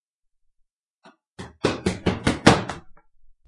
描述：爬楼梯，在家里，赶路Rec Zoom H4
Tag: 渐快 房子 运动 precipitando 仓促 加速 步骤